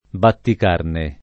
batticarne